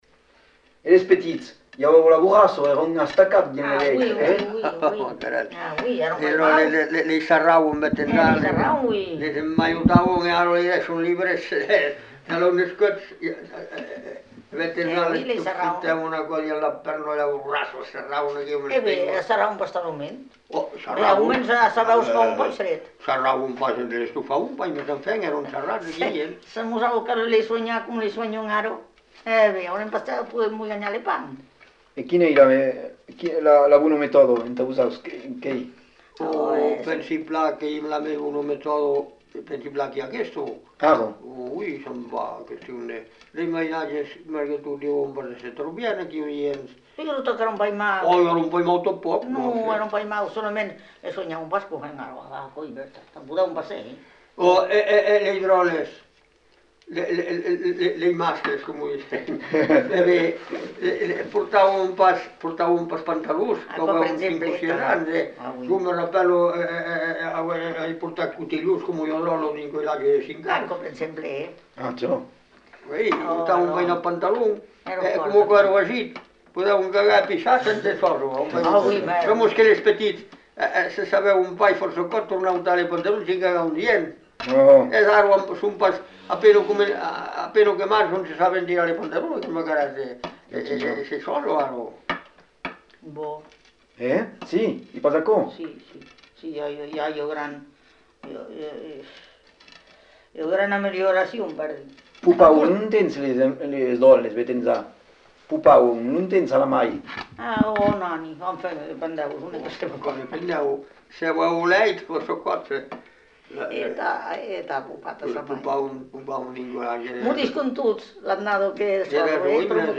Lieu : Cazaux-Savès
Genre : témoignage thématique